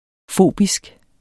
Udtale [ ˈfoˀbisg ]